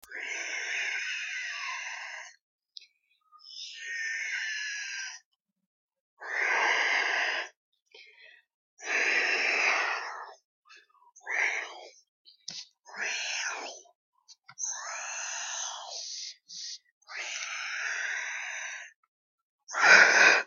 Xenomorph Noises Two Sound Button - Free Download & Play